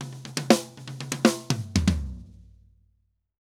Drum_Break 120_4.wav